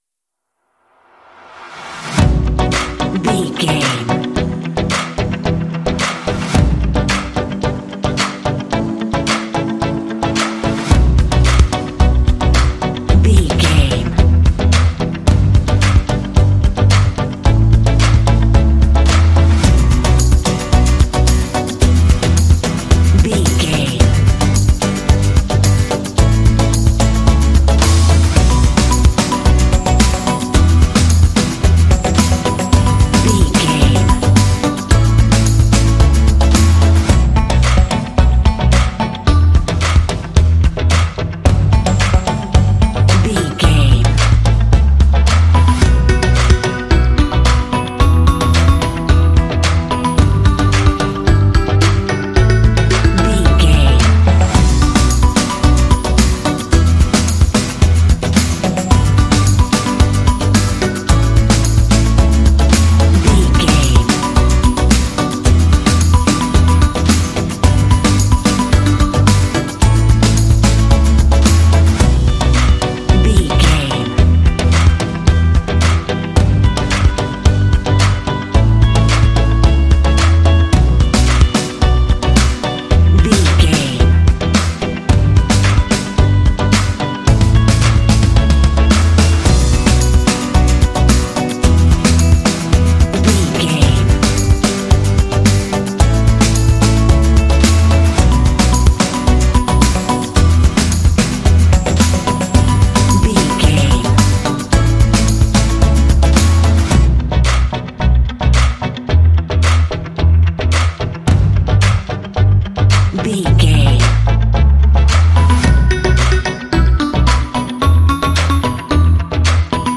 Aeolian/Minor
energetic
dramatic
synthesiser
drums
acoustic guitar
bass guitar
electric guitar
alternative rock
indie